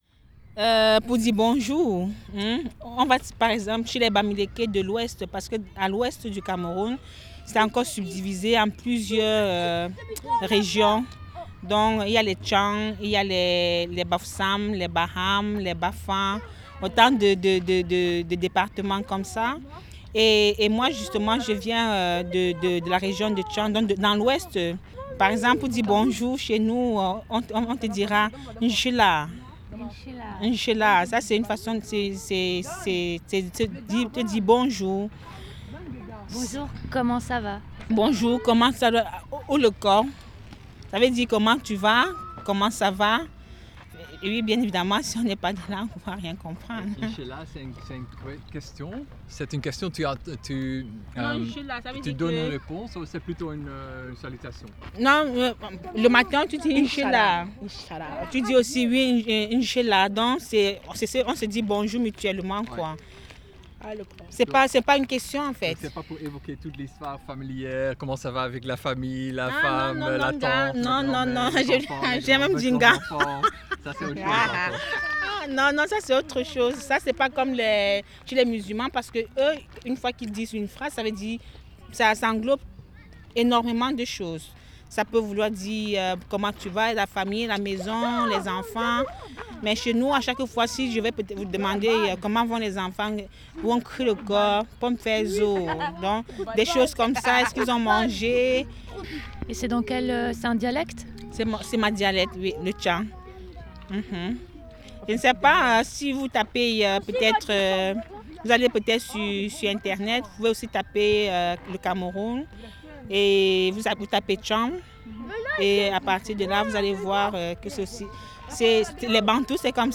explication